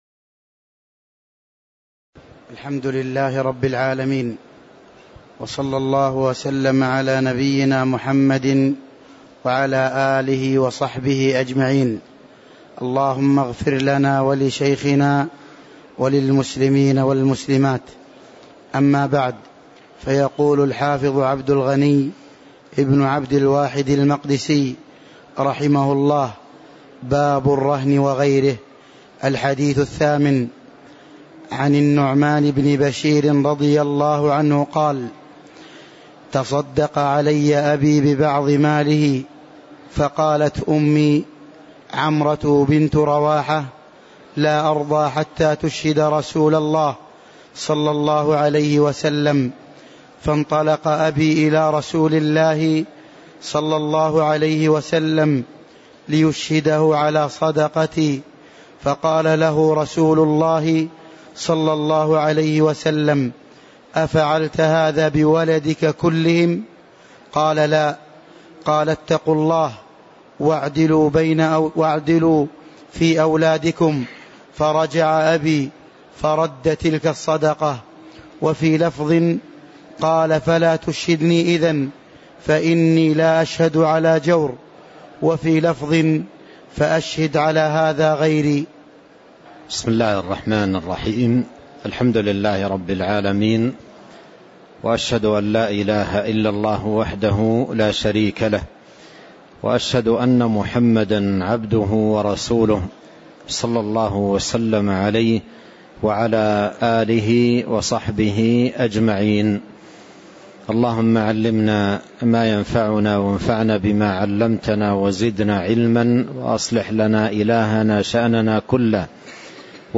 تاريخ النشر ٩ رجب ١٤٤٤ هـ المكان: المسجد النبوي الشيخ